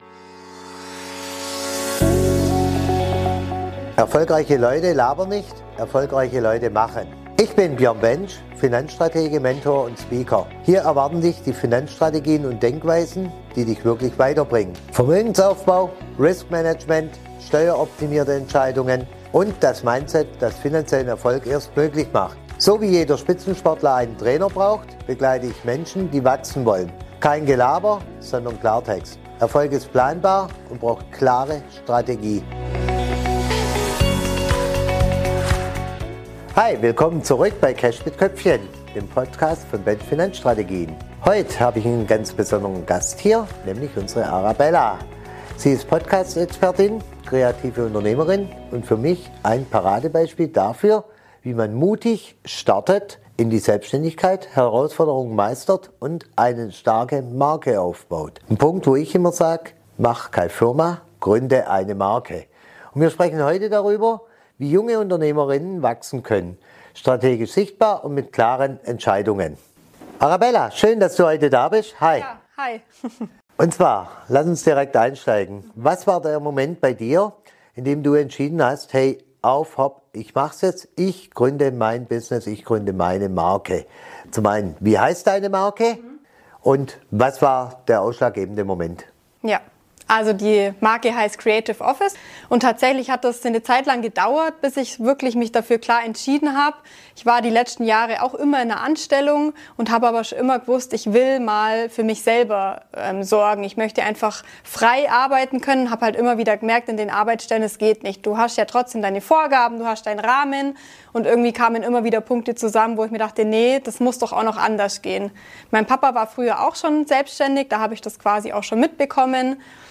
Ein ehrliches Gespräch über Mut, Verantwortung, finanzielle Sicherheit und unternehmerisches Denken – ohne Schönreden, aber mit Perspektive.